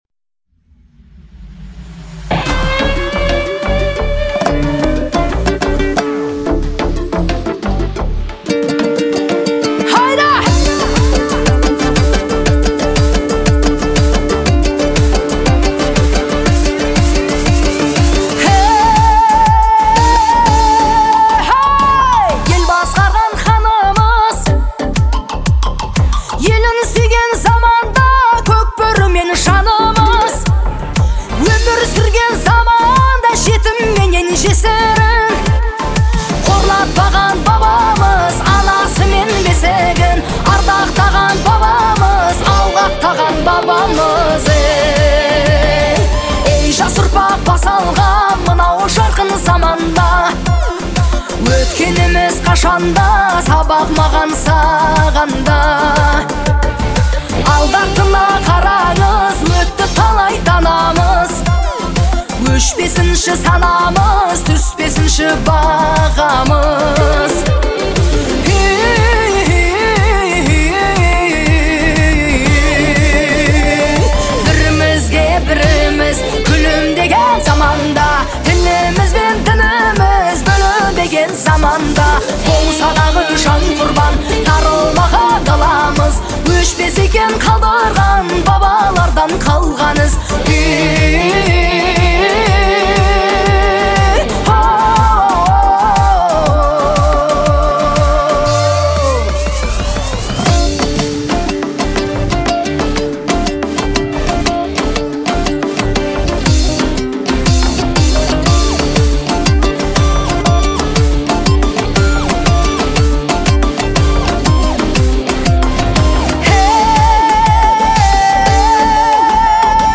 Звучание трека отличается мелодичностью и эмоциональностью